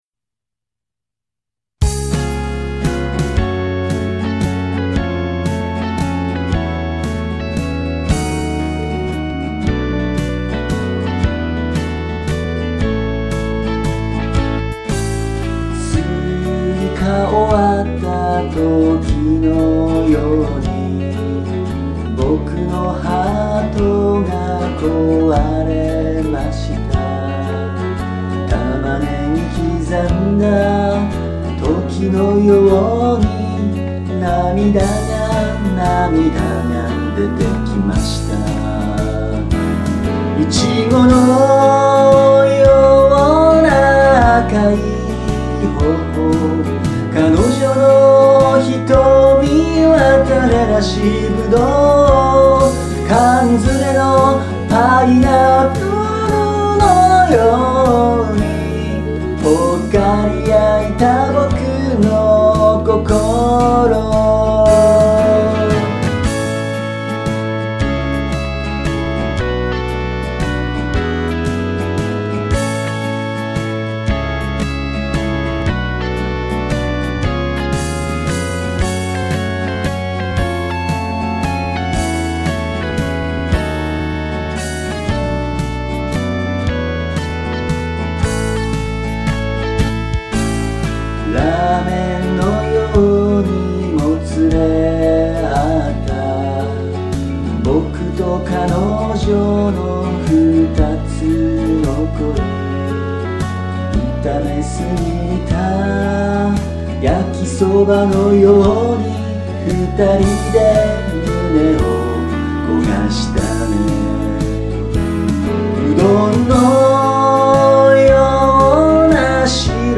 ↓PLAYER(ギター版β)↓